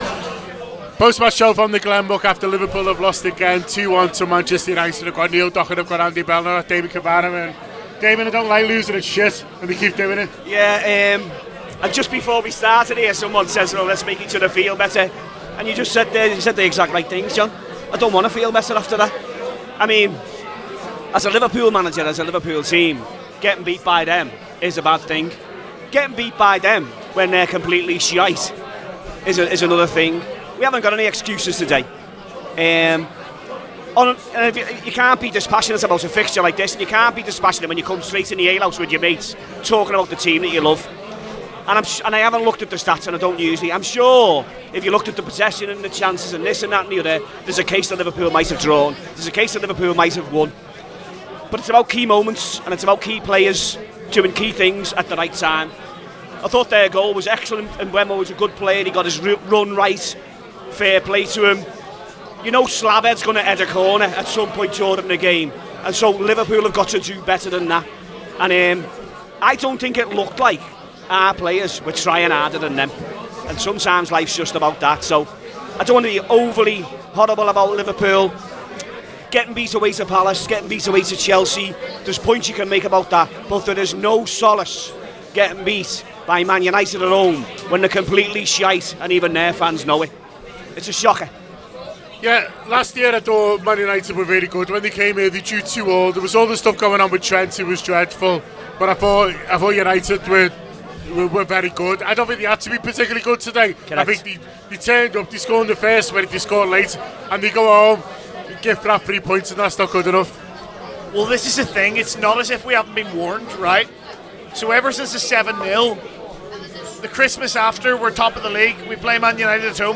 Below is a clip from the show – subscribe to The Anfield Wrap for more on the 20 x Champions Of England